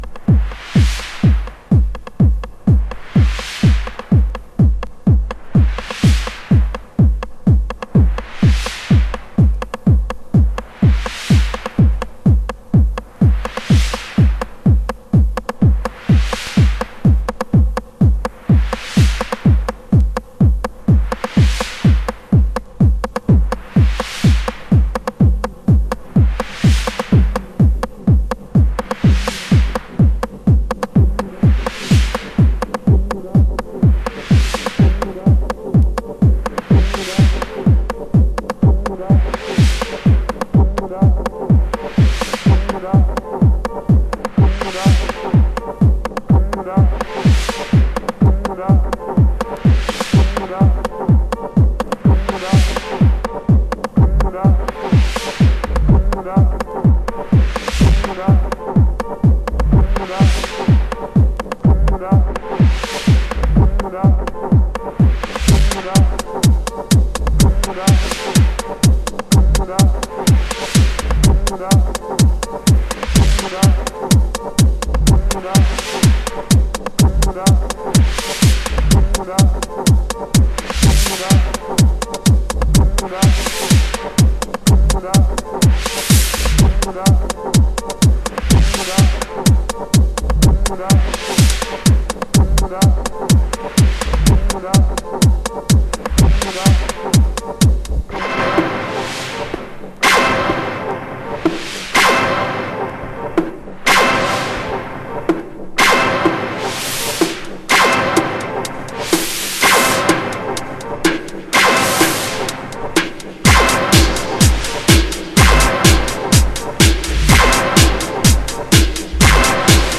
アナログに拘りぬいて90'sを昇華したマシーナリーテクノ。荒い粒子が飛び散る音像がこの方達の持ち味ですね。